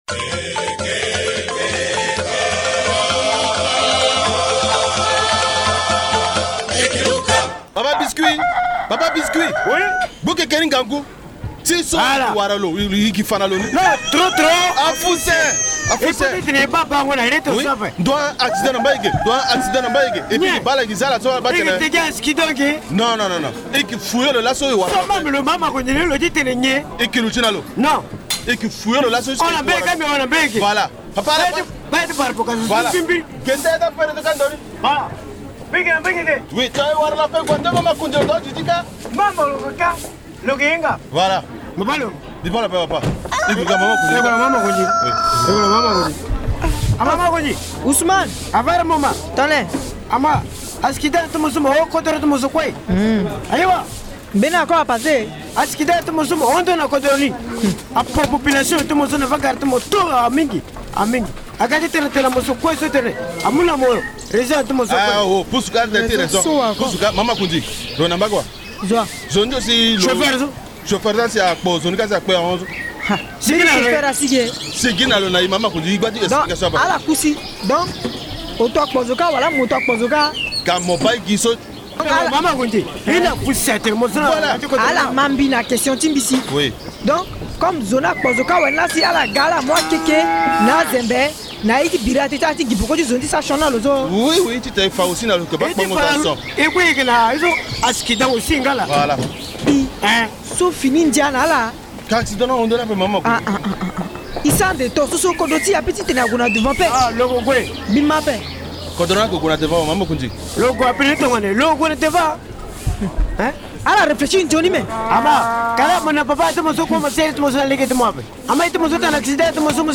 Les comédiens attirent donc l’attention sur cette mauvaise pratique.